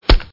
pistolSingle.mp3